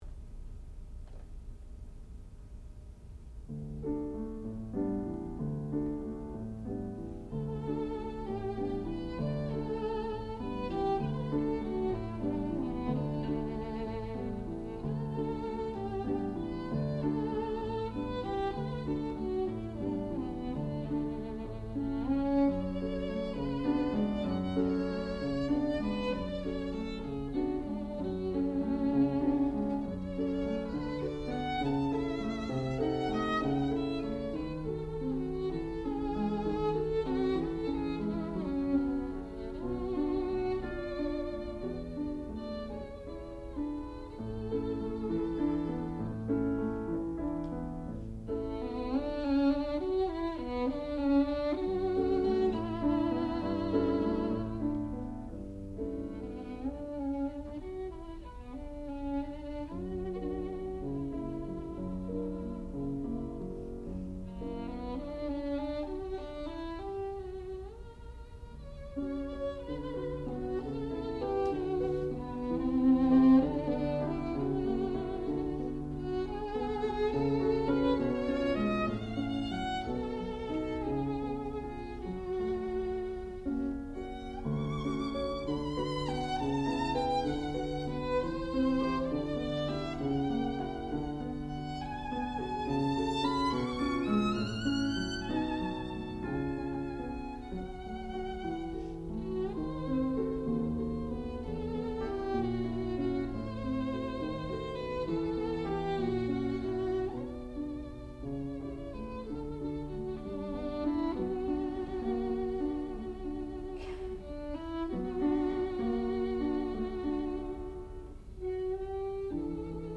sound recording-musical
classical music
violin
piano